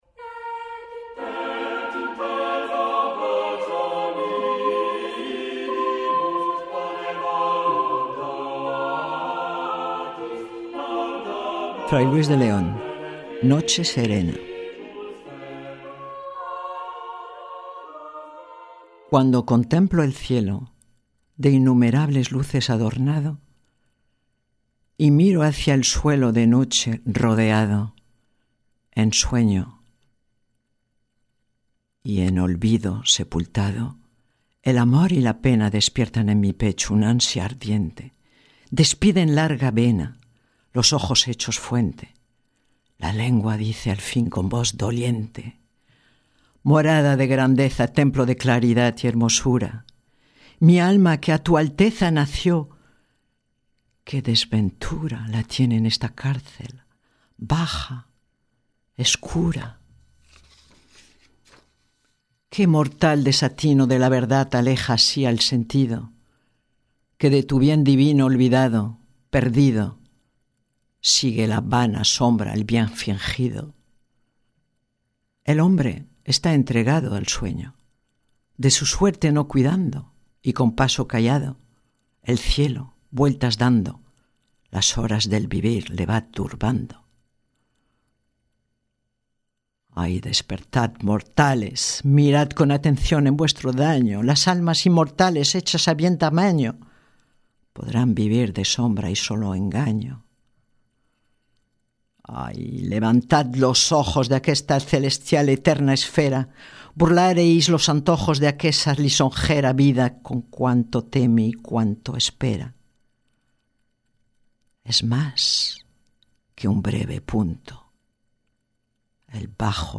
Diffusion distribution ebook et livre audio - Catalogue livres numériques
Ils sont groupés selon deux thèmes, Au pied de la Croix et Les Poésies amoureuses de saint Jean de la Croix, Thérèse d'Ávila, Luis de Grenada, Fray Luis de Leon et Miguel Unamuno. Lus en espagnol, puis en français, l'auditeur aura le choix d'écouter en version originale ou en français les poèmes intemporels de ces « Fols en Christ ».